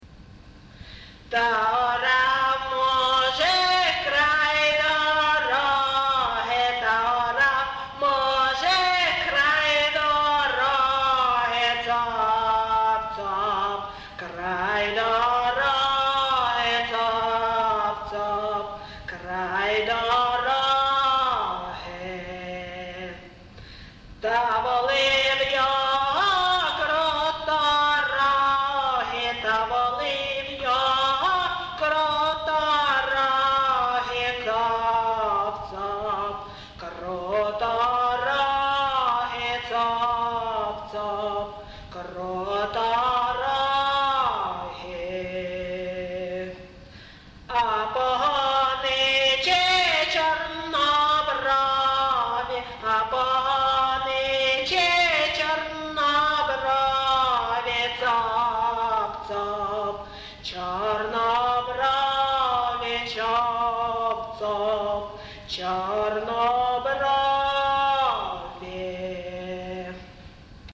ЖанрЖартівливі
Місце записус. Ковалівка, Миргородський район, Полтавська обл., Україна, Полтавщина